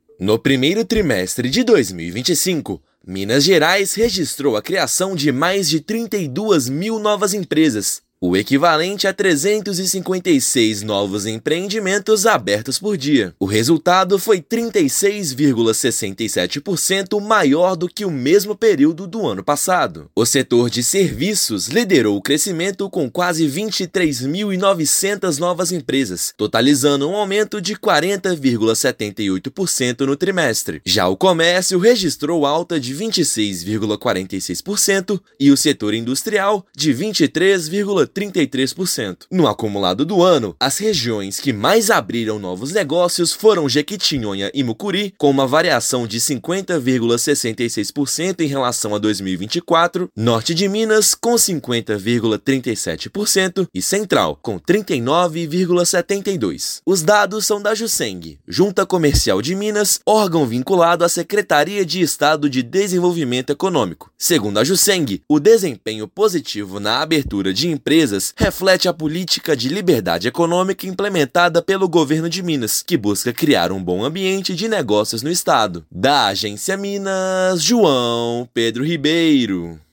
Foram mais de 32 mil novos negócios formalizados em todo o estado, de janeiro a março deste ano. Ouça matéria de rádio.